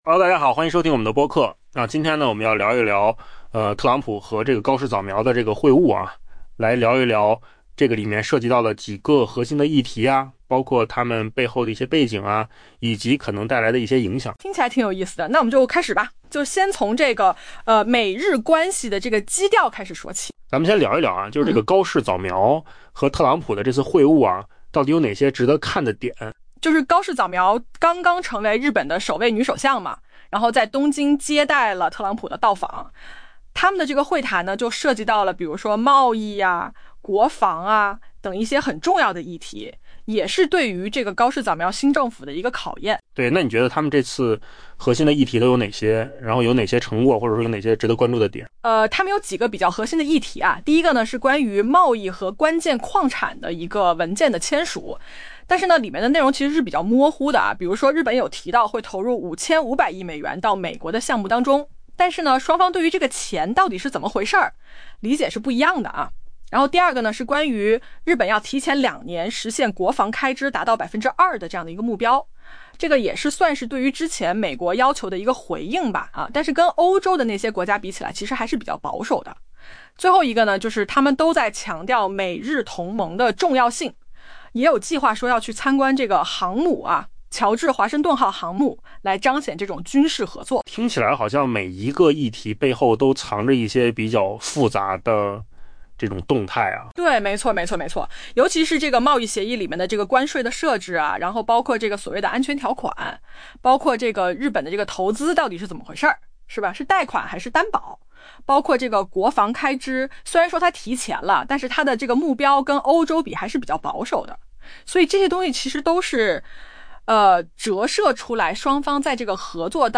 AI 播客：换个方式听新闻 下载 mp3 音频由扣子空间生成 美国总统特朗普盛赞美国与日本的同盟关系，重申了与这个长期伙伴的联系，并在东京会见新首相高市早苗时，对她提高国防开支的计划表示赞扬。